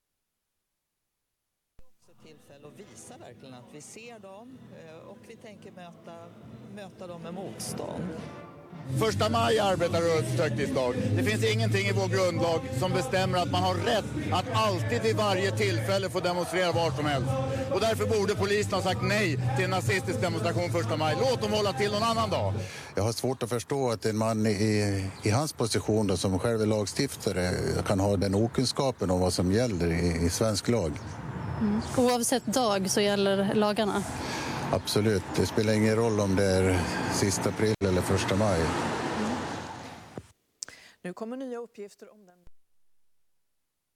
En av de flitigaste gästerna i programmets Nyhetspanel är kommunisten och Vänsterpartiets förra partiledare Lars Ohly, som inte sällan får framföra sina åsikter ganska oemotsagd.